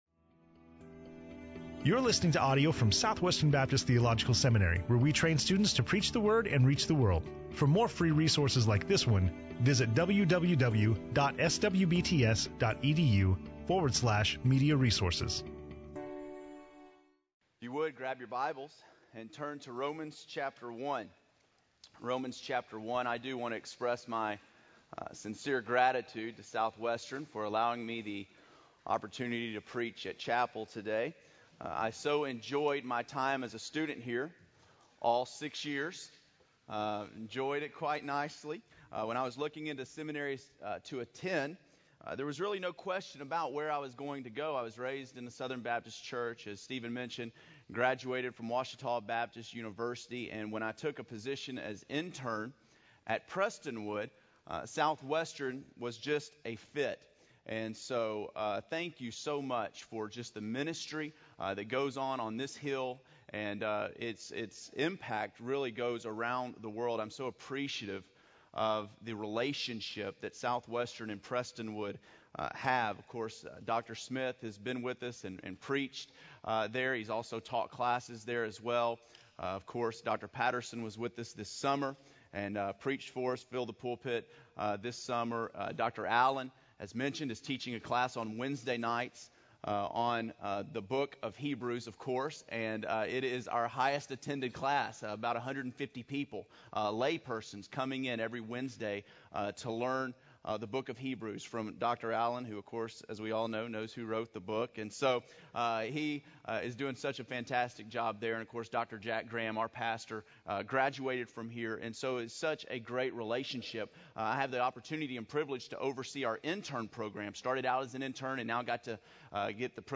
SWBTS Chapel
SWBTS Chapel Audio